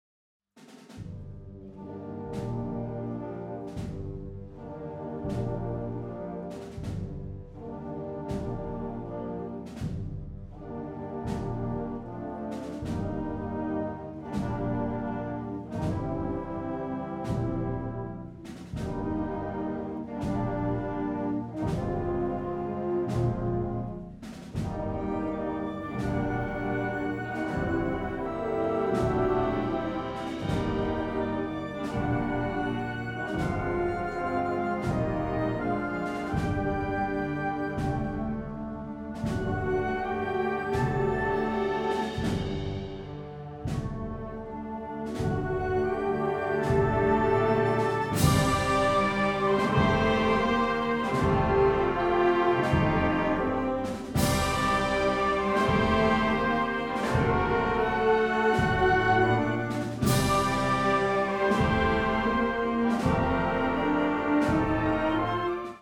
La marcha lenta